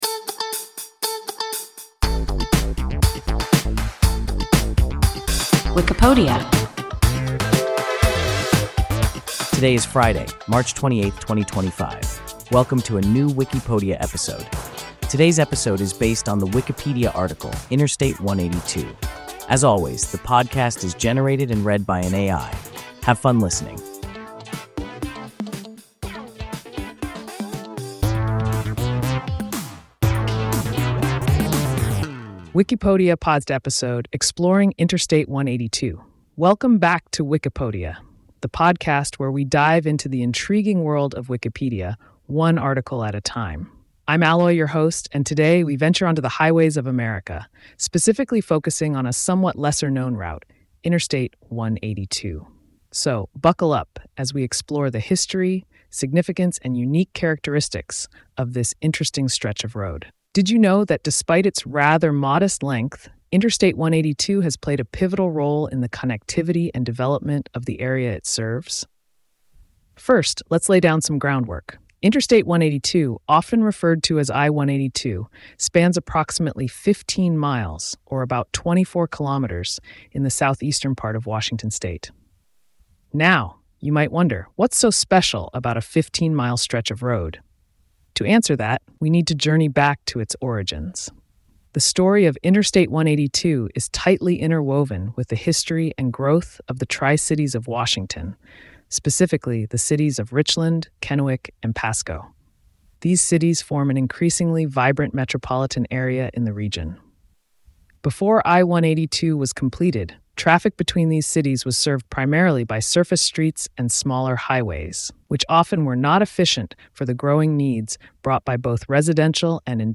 Interstate 182 – WIKIPODIA – ein KI Podcast